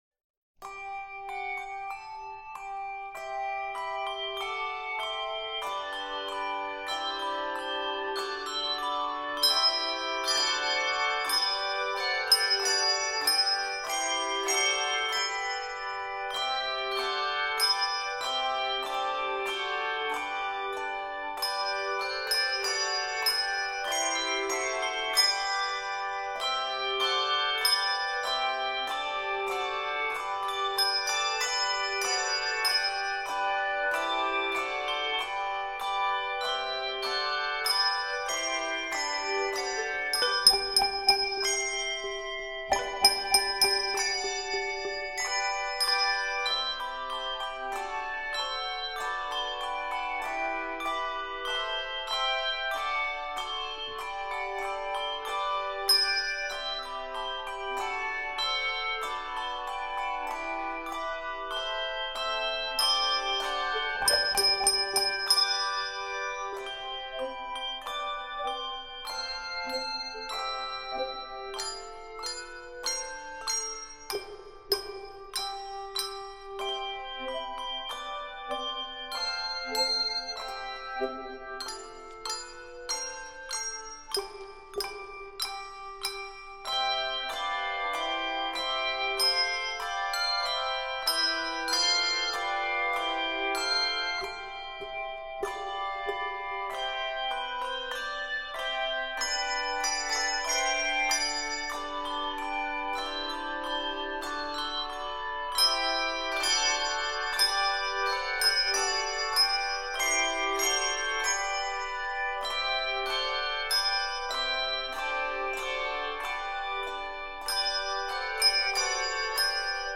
Keys of G Major and C Major.